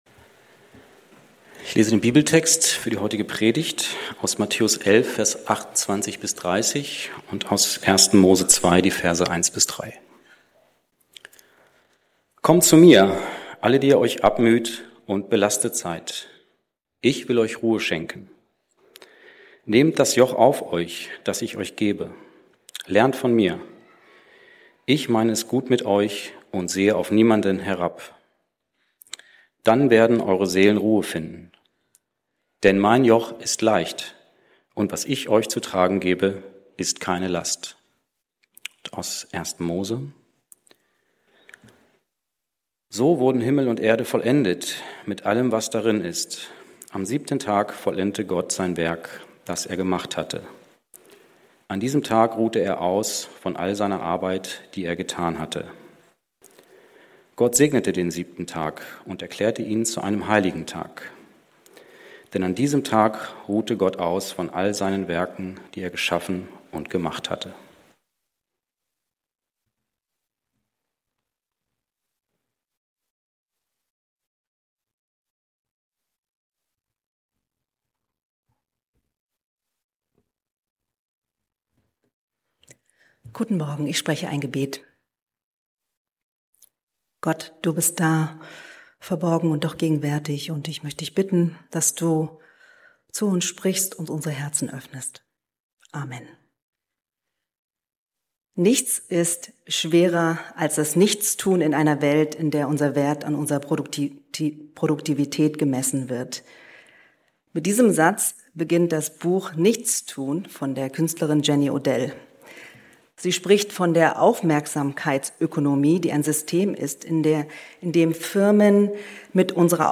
Ein Lebensrhythmus der gut tut ~ Berlinprojekt Predigten Podcast